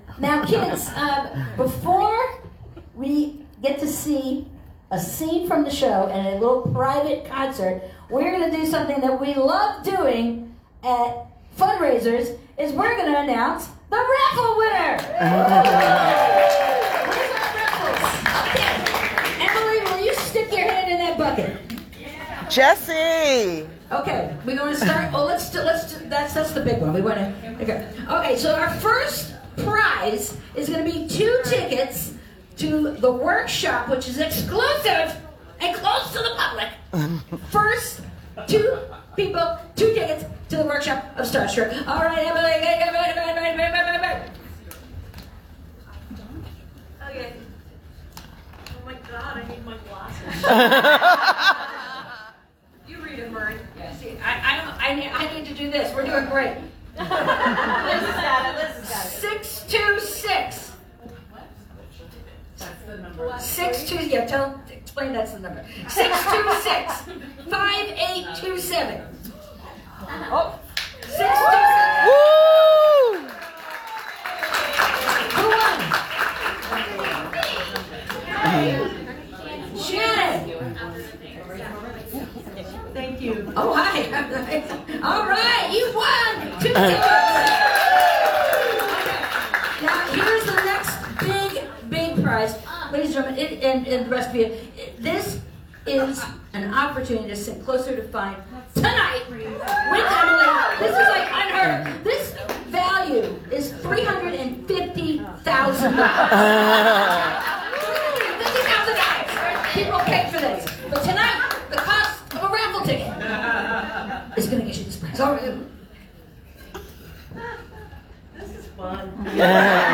lifeblood: bootlegs: 2024-05-24: out of the box theatrics - new york, new york (emily saliers)
02. raffle drawings (3:37)